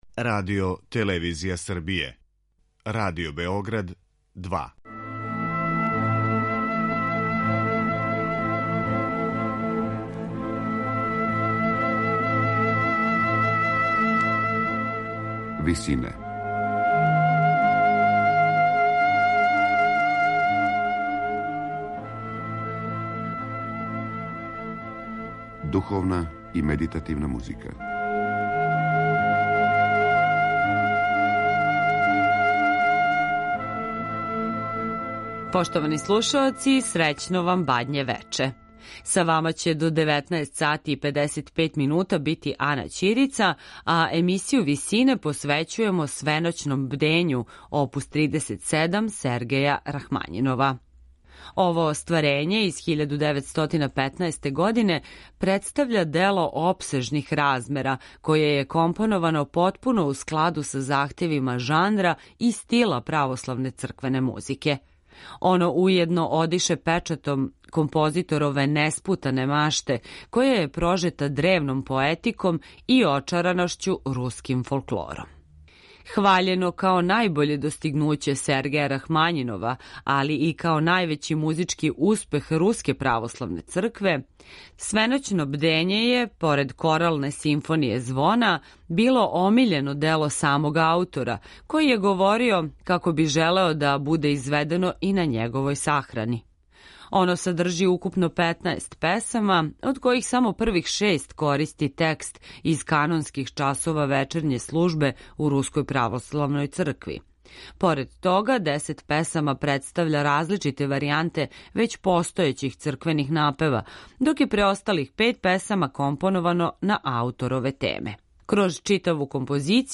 монументалног хорског остварења
мецосопрана
тенора